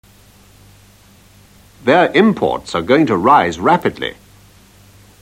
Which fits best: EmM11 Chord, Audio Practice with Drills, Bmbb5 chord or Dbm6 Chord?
Audio Practice with Drills